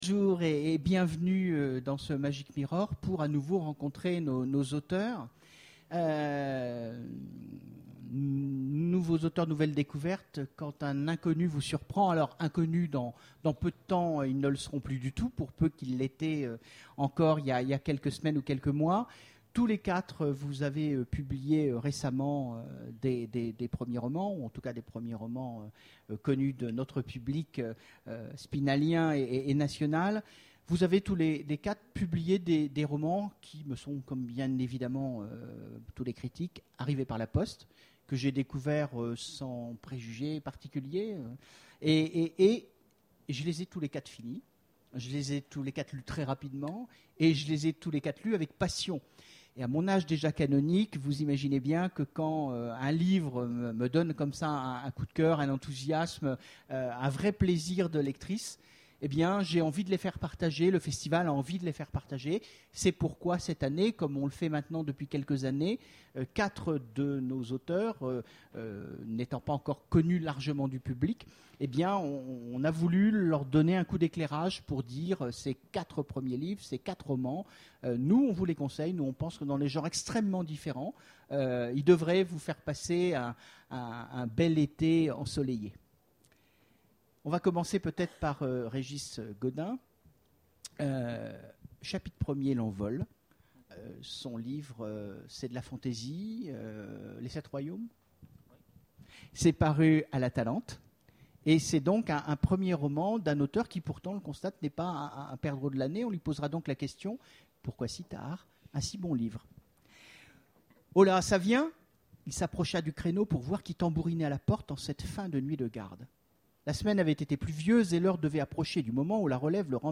Imaginales 2013 : Conférence Premiers romans, nouveaux talents